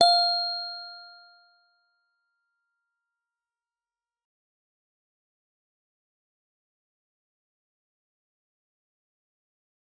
G_Musicbox-F5-f.wav